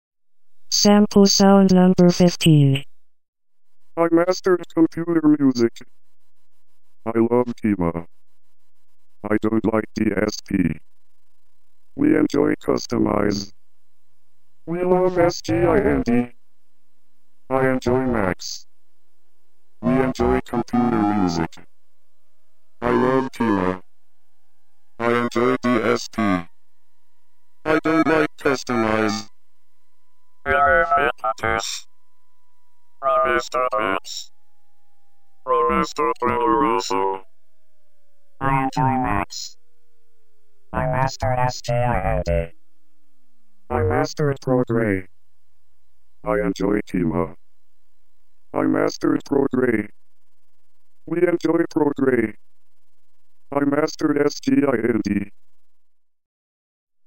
●ライブ入力へのAMとRM（
ここでは全部で20フレーズのランダムスピーチ入力に対して、前半 はAMとしてトレモロ効果の低周波から1000Hzまで次第に上昇させ、 11個目の後半からは定数をゼロとしてRMに切り替え、キャリア周波数 を1000Hzから1Hz以下まで次第に下降させた。